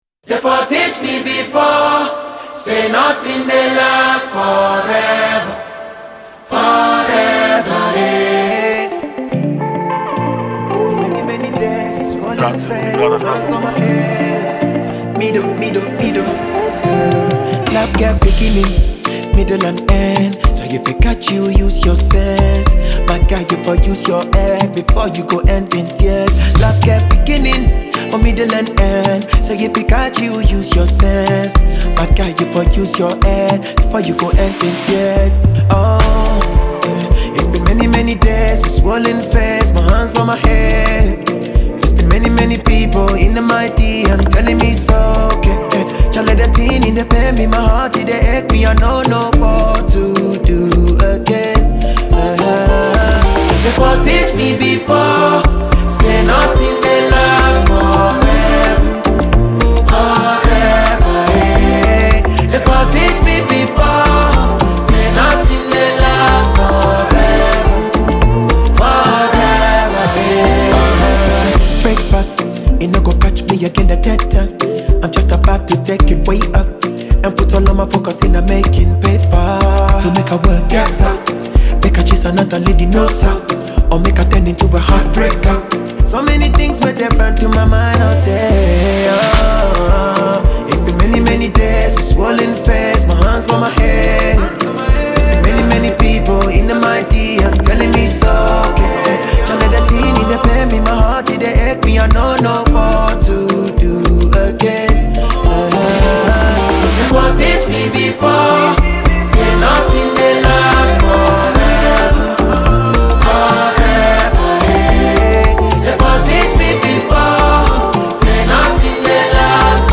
a multiple award-winning Ghanaian singer